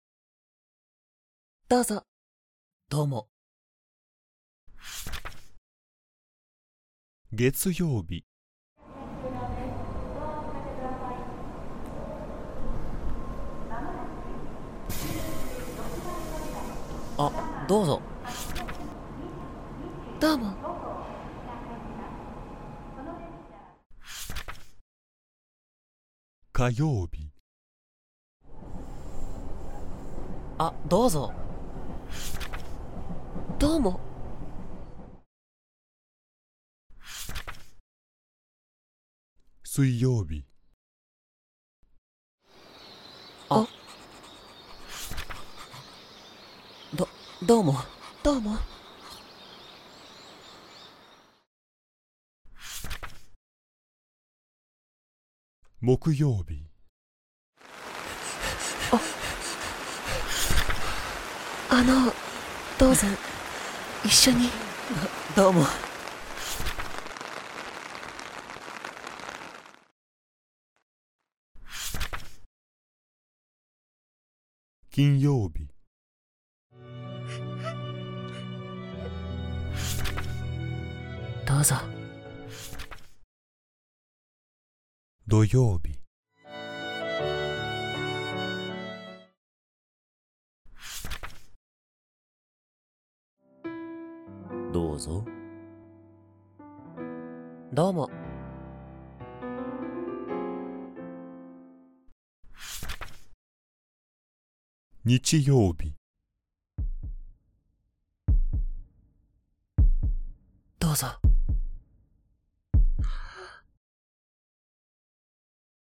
Graded readers
朗読音声付き